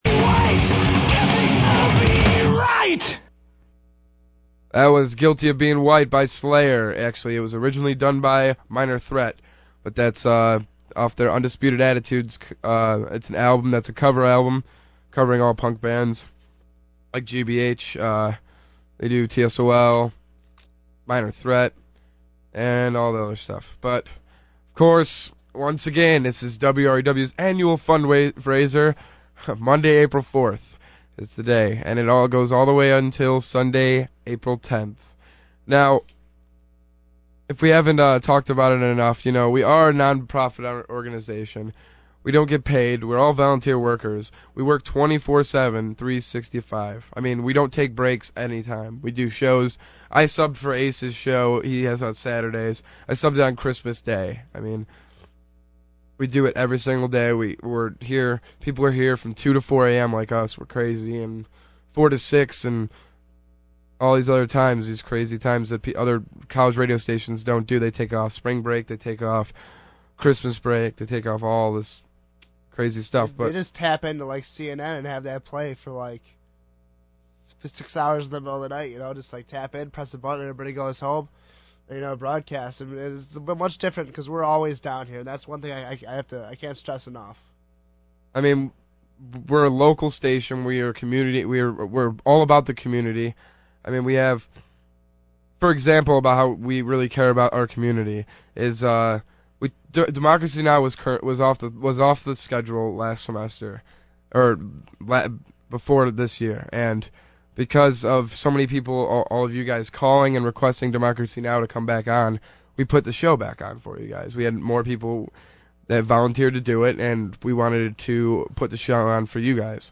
• Listen to how positive and upbeat each programmer is when talking about the fundraiser.